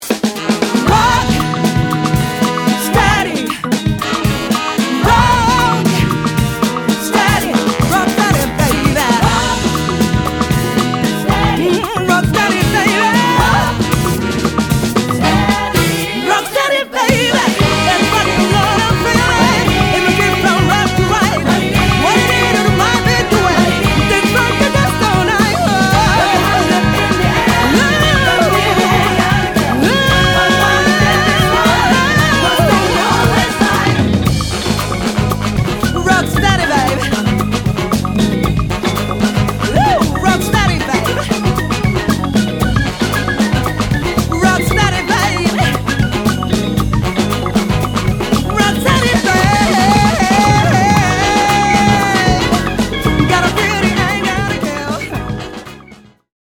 ジャンル(スタイル) JAPANESE / SOUL / FUNK